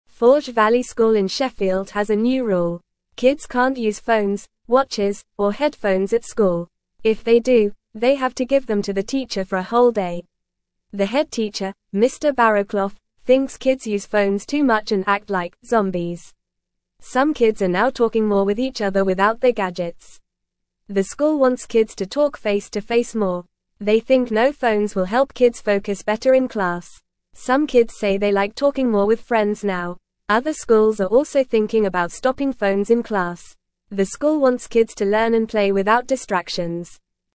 Fast
English-Newsroom-Beginner-FAST-Reading-No-Phones-Allowed-at-Forge-Valley-School-in-Sheffield.mp3